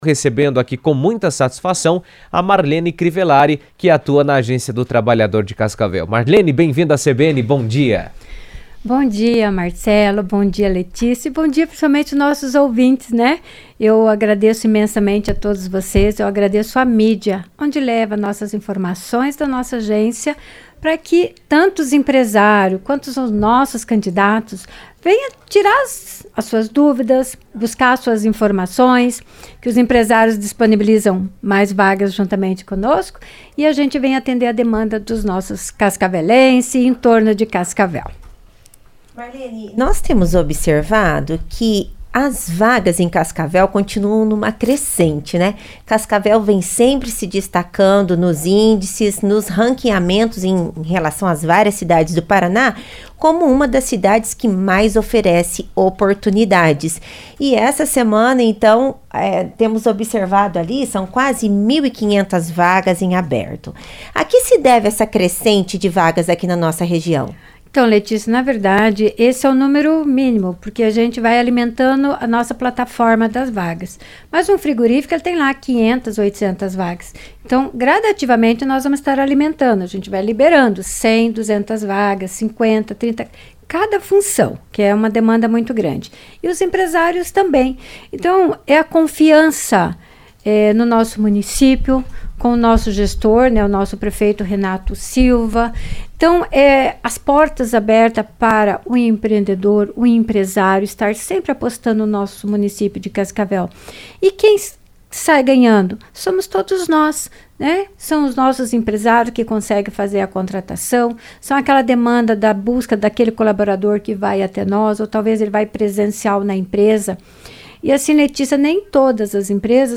O mercado de trabalho segue em crescimento em Cascavel, com destaque para as oportunidades temporárias no comércio para o fim de ano. Em entrevista à CBN